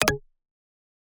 pda_beep_2.ogg